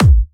VEC3 Bassdrums Trance 31.wav